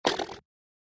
SFK一个简单的小军鼓/拍子的声音和一些变化" 声音 var 17
描述：snaree，拍手，sfx打击乐打击乐一次性perc percssive
Tag: 敲击 拍手 的PERC snaree 打击乐器 SFX 打一拍 四氯乙烯